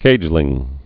(kājlĭng)